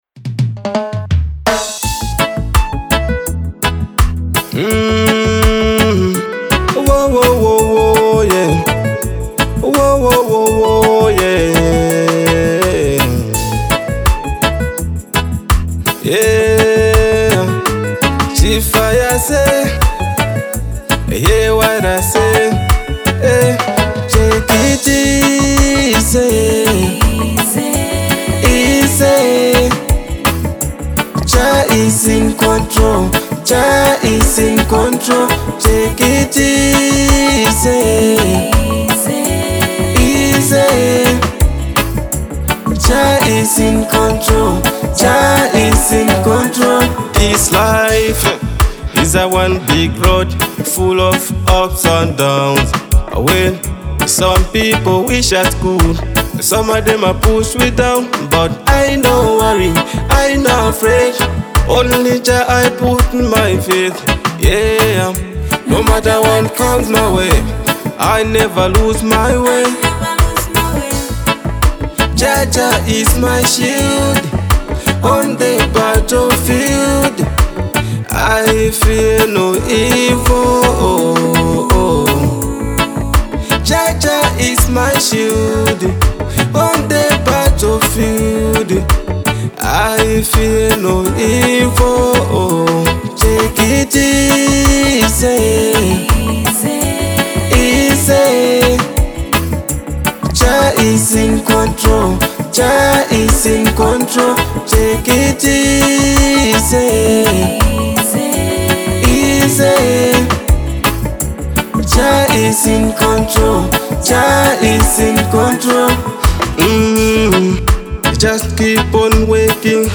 Reggae Dancehall